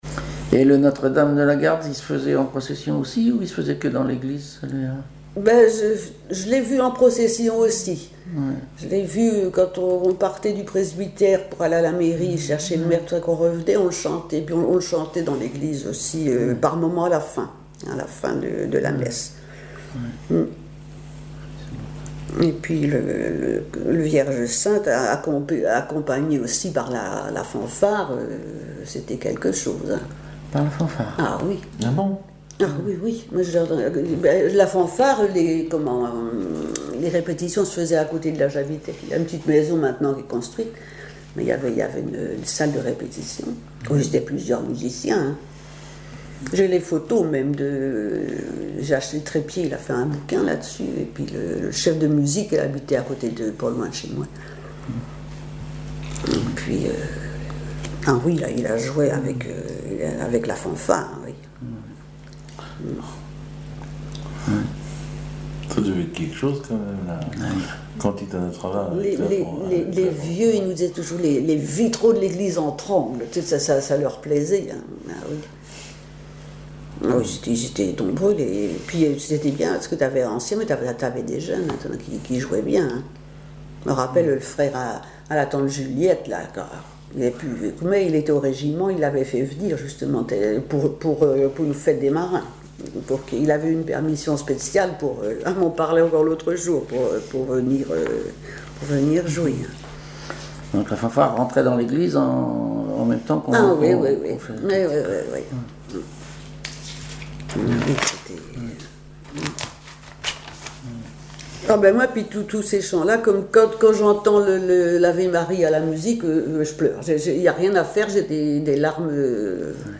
Mémoires et Patrimoines vivants - RaddO est une base de données d'archives iconographiques et sonores.
musique, ensemble musical
Cantiques, chants paillards et chansons